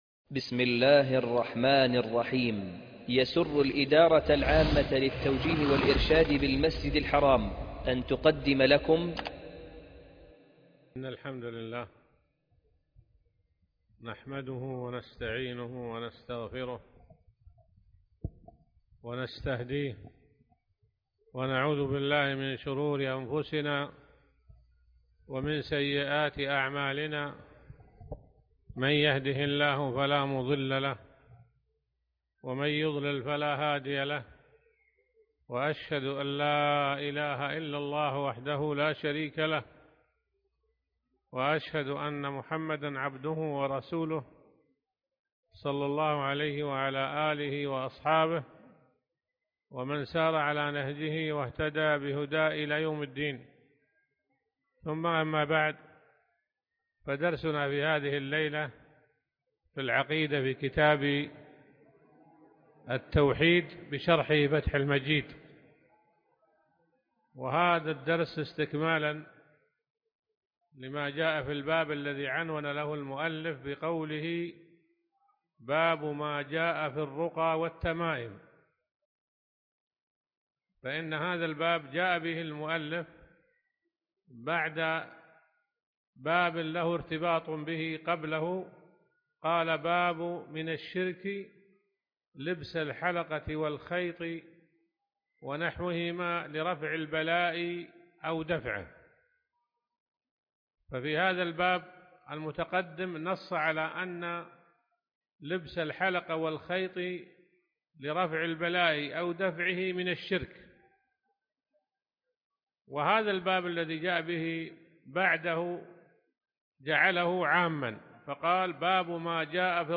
الدرس 4 (فتح المجيد)